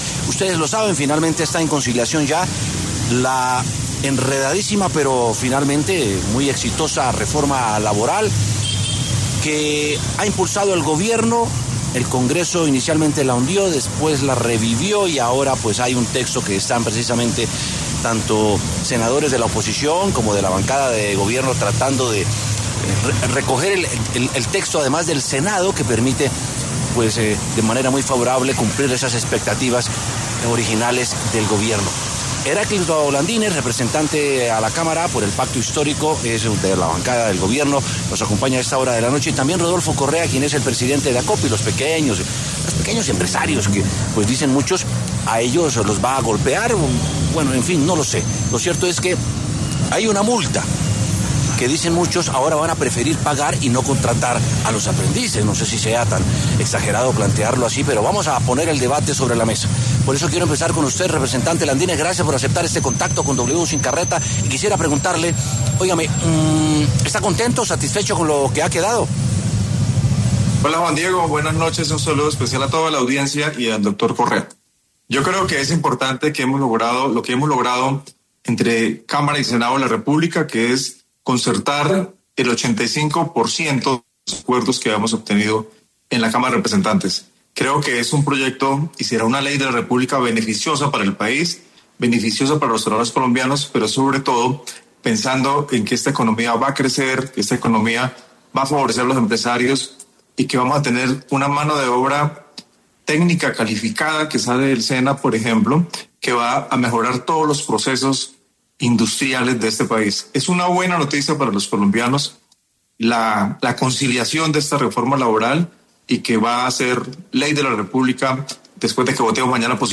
Debate: ¿a quiénes beneficia y a quienes ‘golpea’ la reforma laboral?
W Sin Carreta expuso dos visiones diferentes: una, la de los pequeños empresarios; otra, la de la bancada del Gobierno.